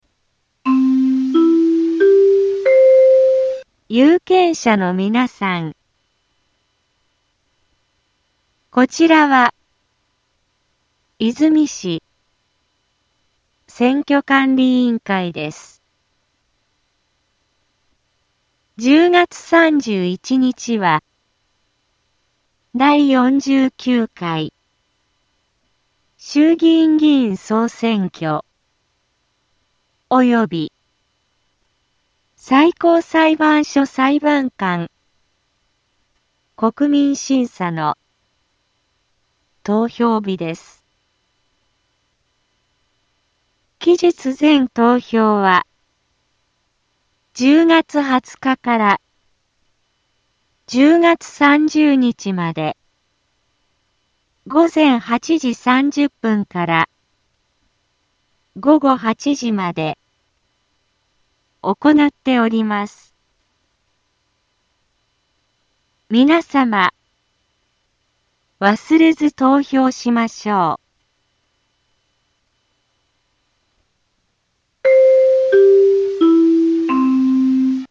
BO-SAI navi Back Home 災害情報 音声放送 再生 災害情報 カテゴリ：通常放送 住所：大阪府和泉市府中町２丁目７−５ インフォメーション：有権者のみなさん こちらは和泉市選挙管理委員会です １０月３１日は、第４９回衆議院議員総選挙及び最高裁判所裁判官国民審査の投票日です 期日前投票は、１０月２０日から１０月３０日まで午前８時３０分から午後８時まで行なっております 皆様、忘れず投票しましょう